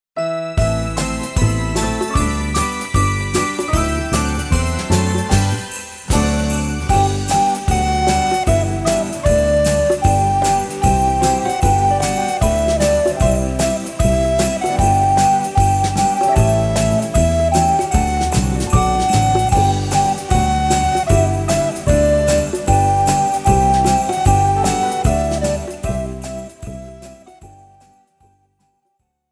Besetzung: 1-2 Sopranblockflöten